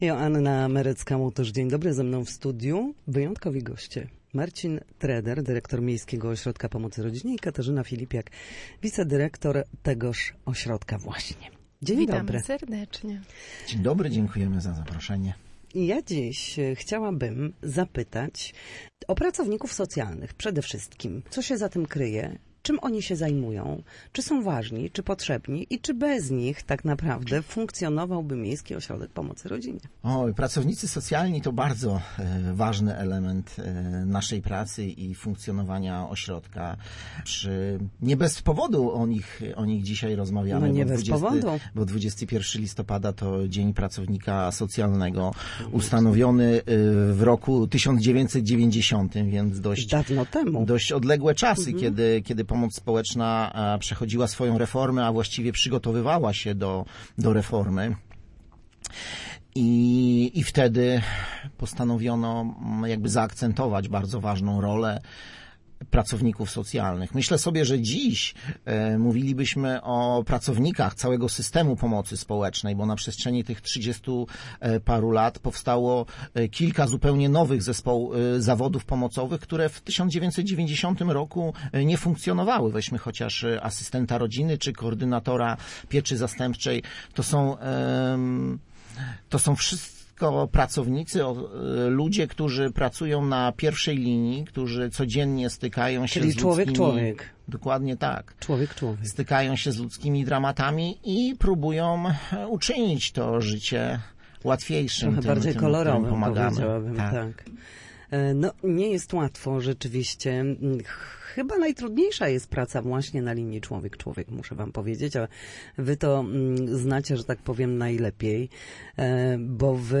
Na naszej antenie mówili o zadaniach, które są w obowiązku pracownika socjalnego.